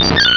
Cri de Tournegrin dans Pokémon Rubis et Saphir.